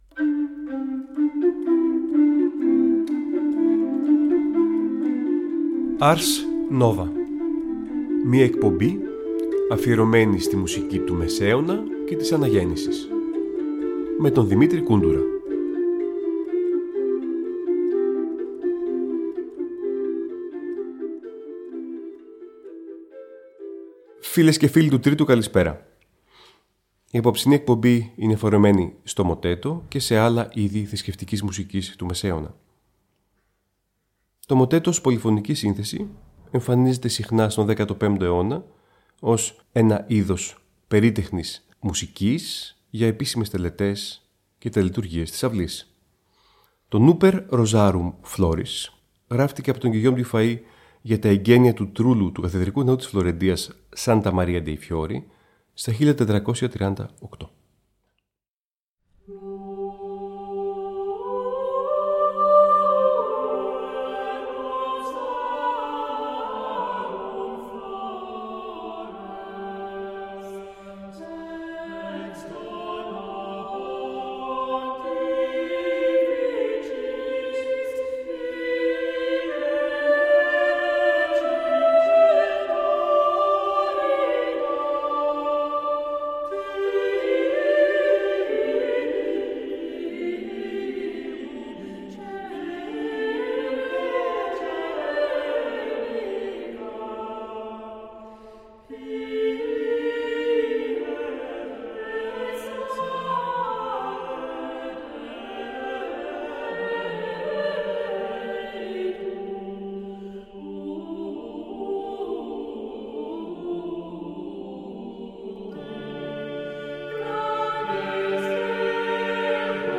Οι Μουσικοί Θησαυροί του Μεσαίωνα και της Αναγέννησης
Νέα ωριαία μουσική εκπομπή του Τρίτου Προγράμματος που μεταδίδεται κάθε Τρίτη στις 19:00.